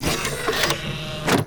doorc.wav